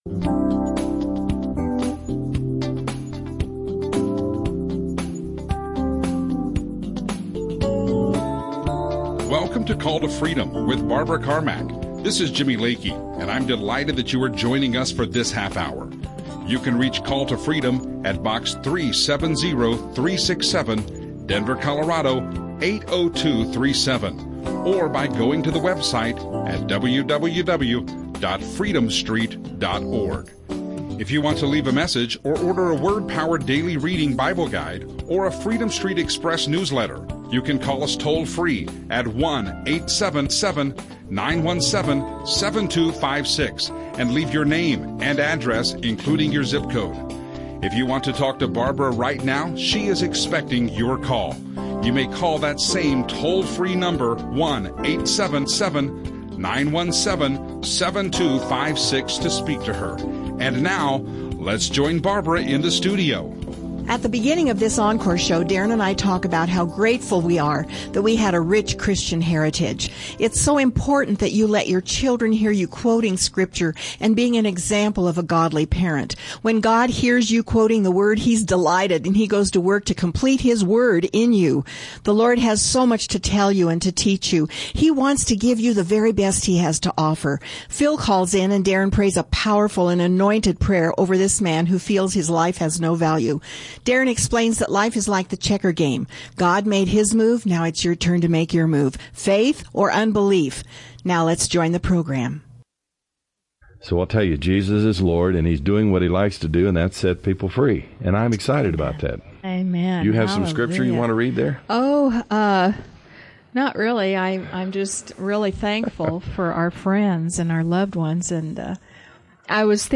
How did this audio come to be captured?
They take phone calls and brings answers of freedom to the listeners.